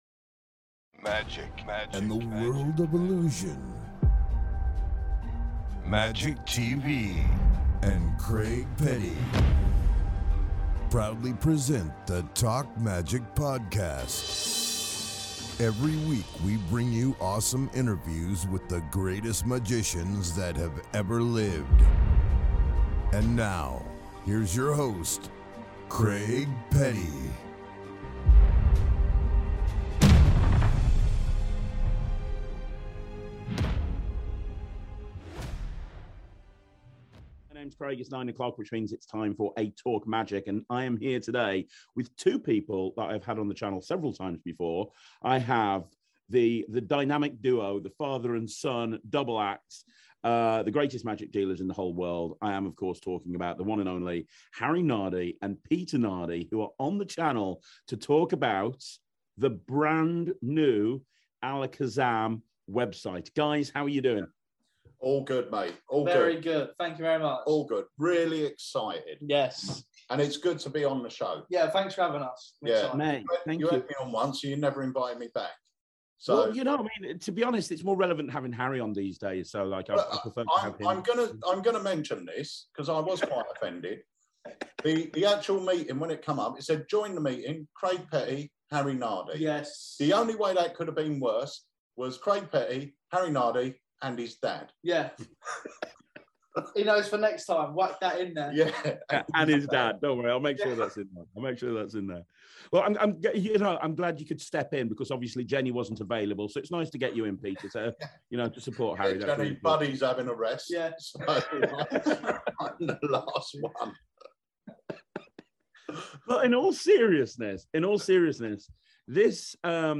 This is an interview you really don't want to miss.